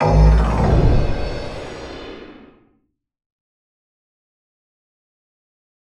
Index of /musicradar/impact-samples/Processed Hits
Processed Hits 08.wav